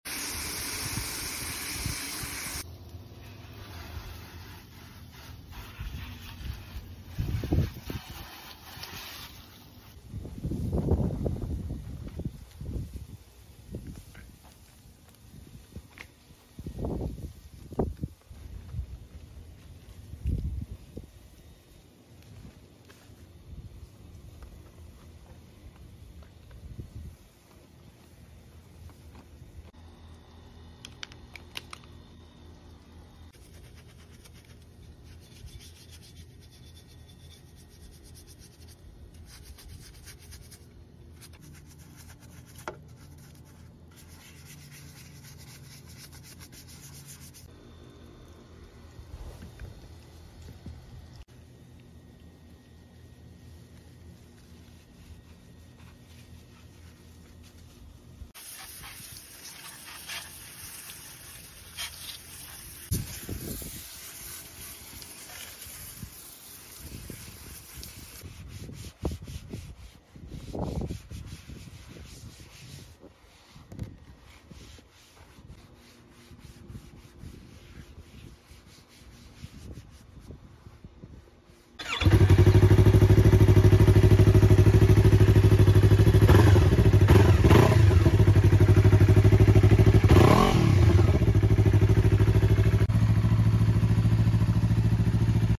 bike cleaning asmr ??? sound effects free download